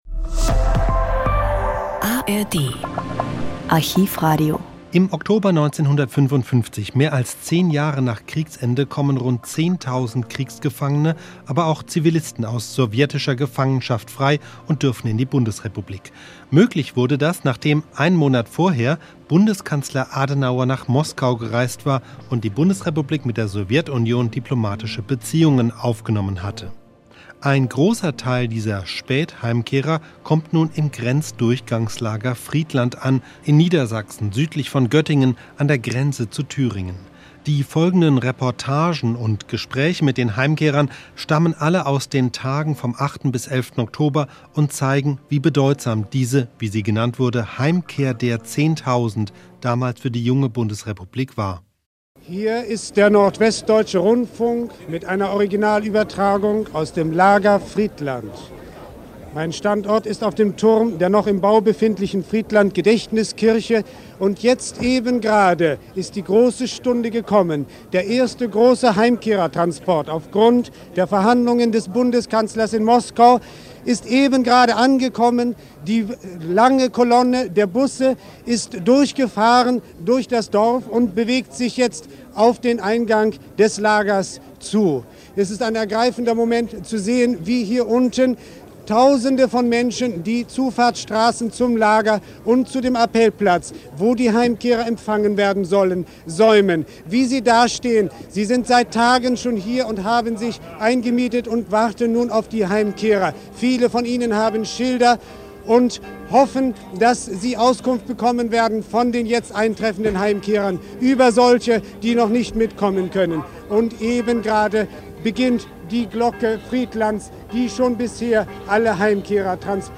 Historische Aufnahmen und Radioberichte von den ersten Tonaufzeichnungen bis (fast) heute.